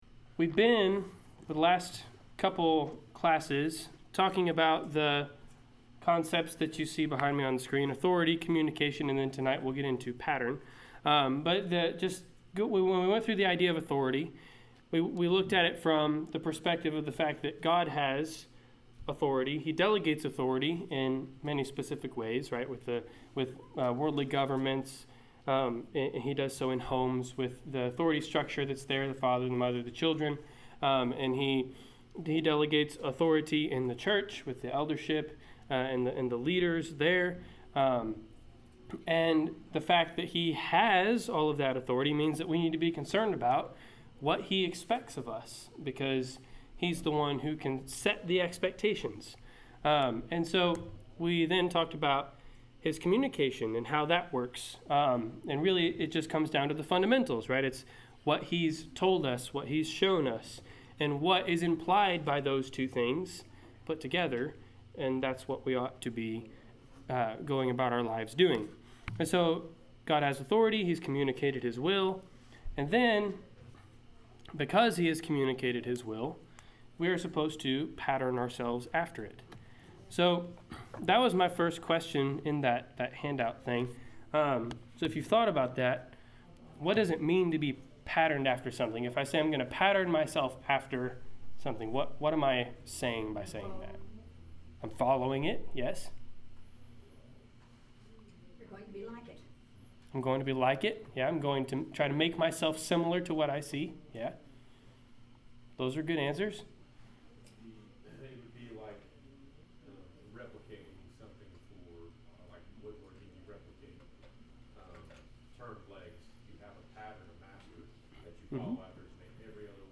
Service Type: Wednesday Night Class Topics: Biblical Authority , Church identity , Patternism